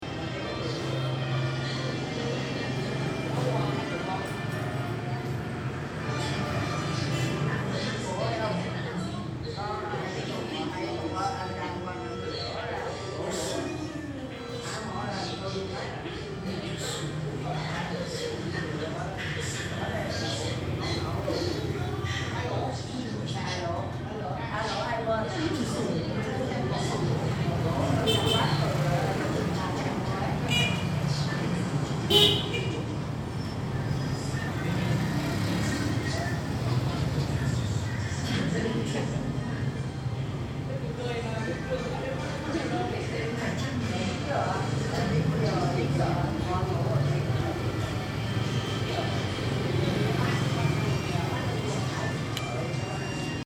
It is five and a half PM, the night is coming. The little streets restaurants put the light on and start to be busy.